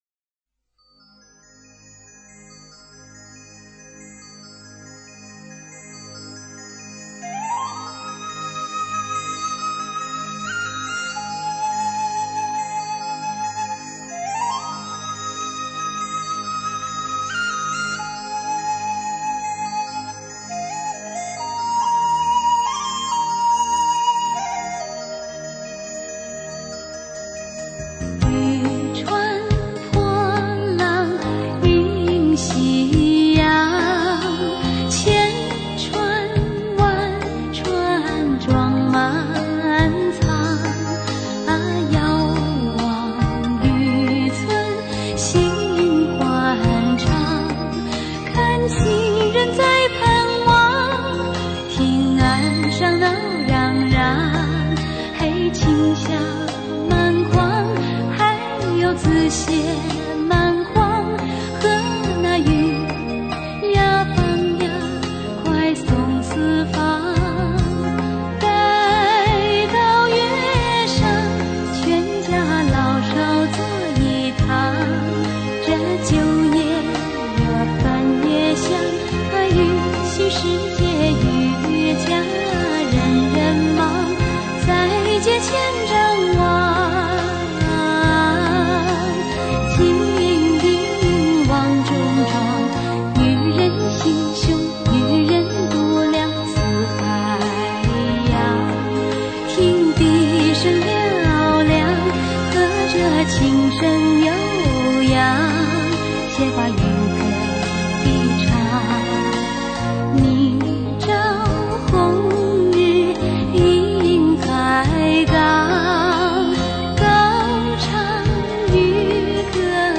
根据同名乐曲改编